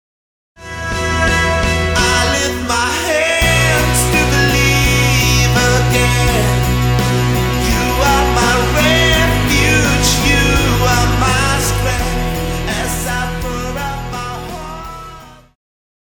Vocal - female,Vocal - male
Band
POP,Christian Music
Instrumental
Solo with accompaniment